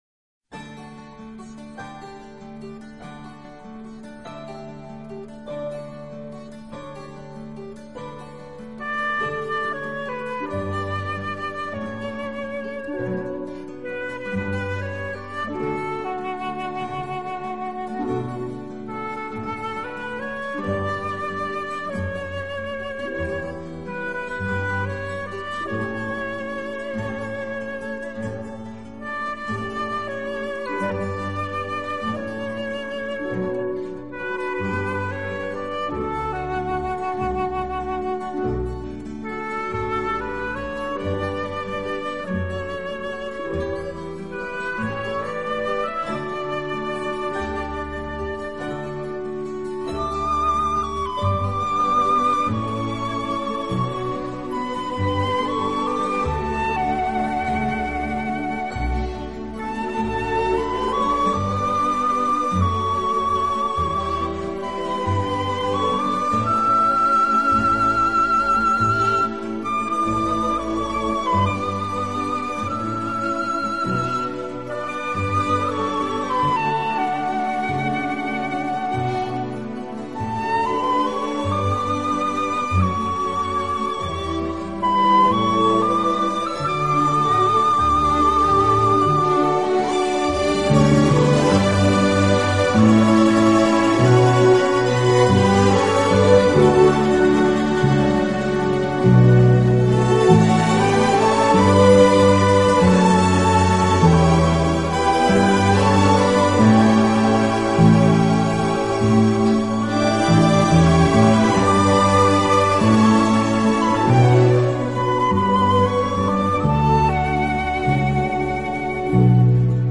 音乐类型：Classical
长笛的音色清冷高雅，宛如一位冰雪美人，低调中透着活泼明丽，圆润而绚丽，
经典长笛名曲，专业数码录音，不可多得的精选。